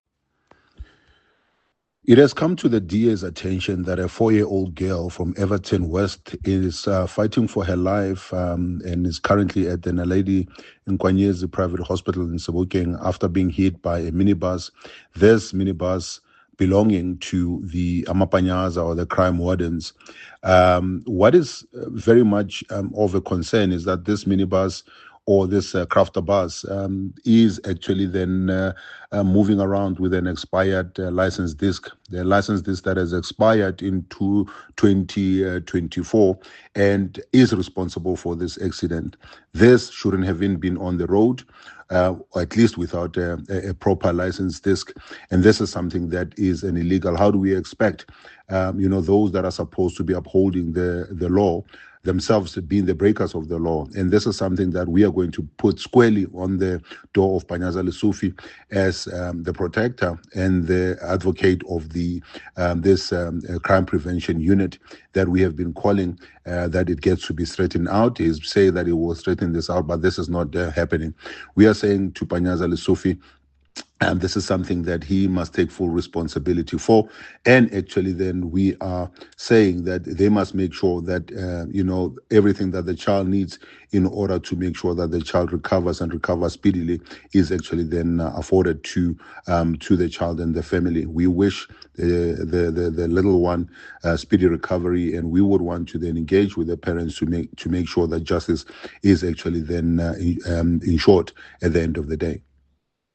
soundbite by Solly Msimanga MPL, DA Gauteng Leader of the Official Opposition.